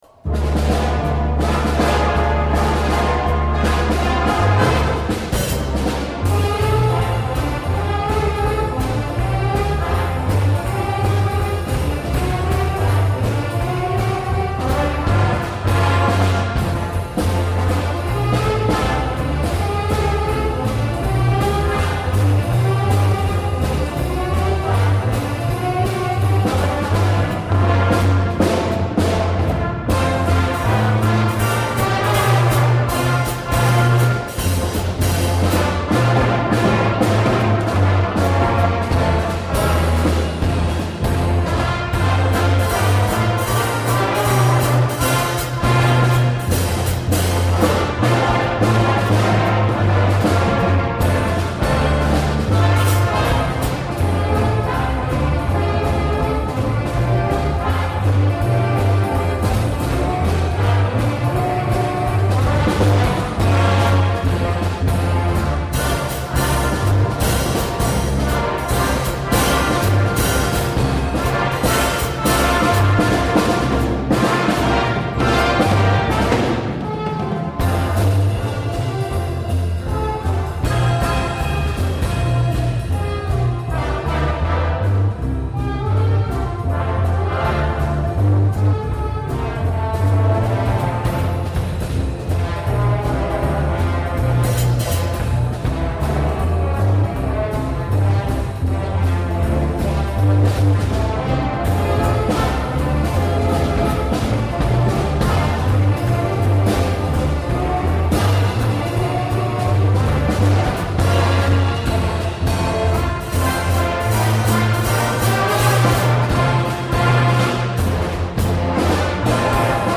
Playing for a Valentines' Day dance at a retirement home, Des Moines, Iowa